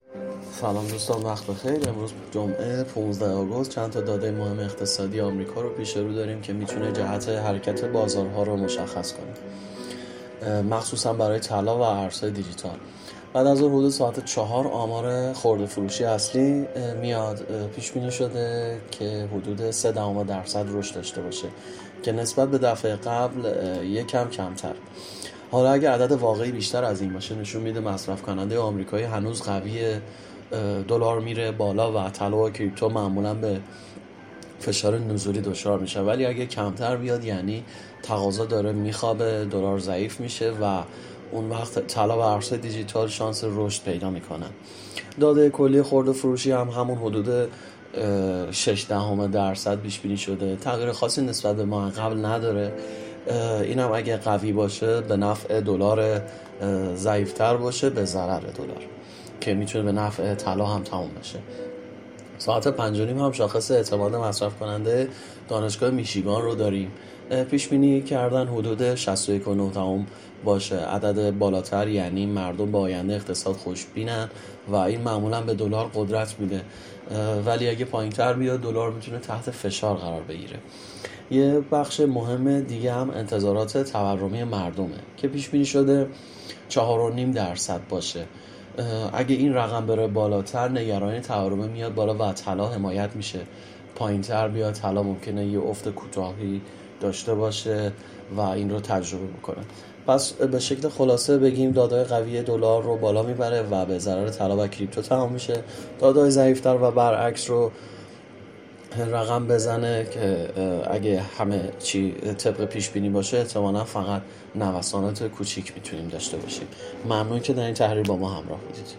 🔸گروه مالی و تحلیلی ایگل با تحلیل‌های صوتی روزانه در خدمت شماست!